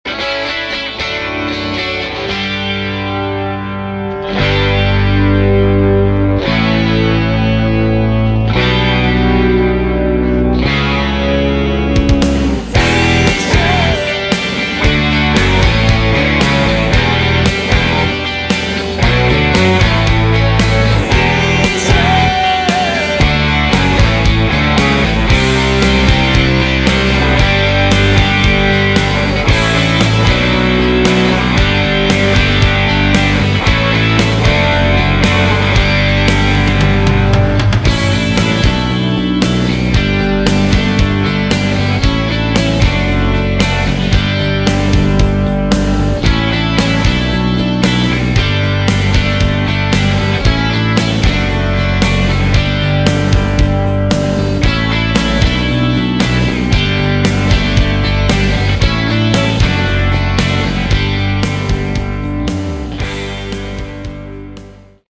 Mixed For TV Inst